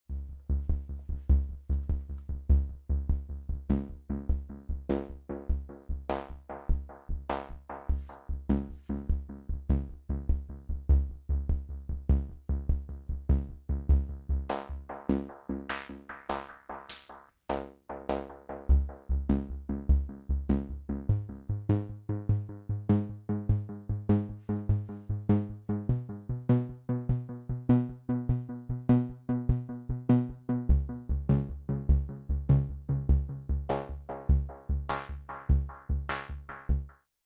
Beautiful delays from the Carbon sound effects free download
A clock triggers the envelope and modulates the filter here.